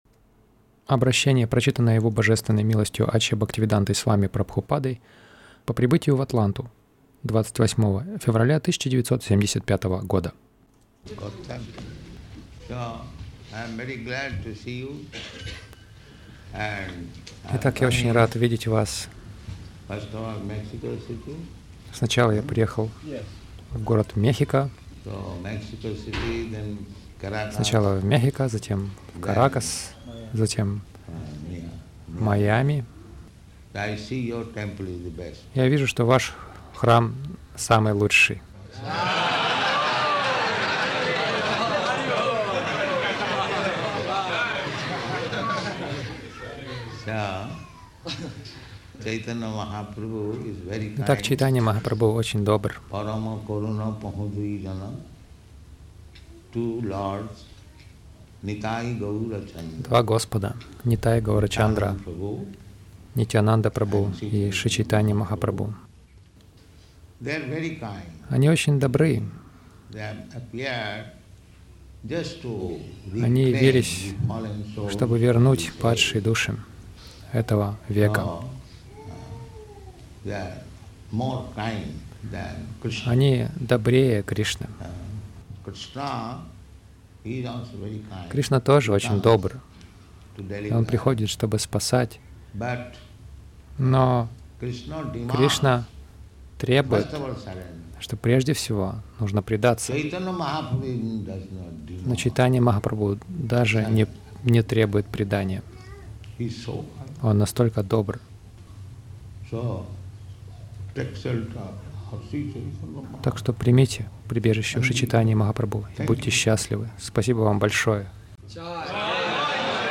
Речь пo прибытию — Примите прибежище у Махапрабху